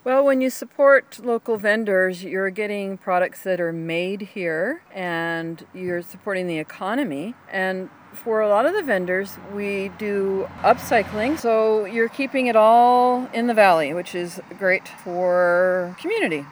That’s one of the key goals when it comes to events like this weekend’s Comox Valley Fall Craft Market.
The action took place at the Fallen Alders Community Hall and a bevy of people showed up to check out the homemade, home-based products vendors had to offer.